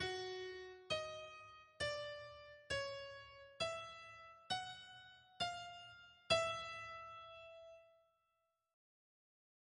Simplicidade: tr�s exemplos complexos ou Complexidade: tr�s exemplos simples [ anterior ] [ pr�xima ] Capa Exemplo 1 Exemplo 2 Exemplo 3 [ ouvir ] Usando um modelo muitas vezes utilizado na m�sica tonal, adicionemos um acompanhamento simples, consistindo apenas do pedal sobre a t�nica e uma voz adicional em sextas paralelas com a linha mel�dica.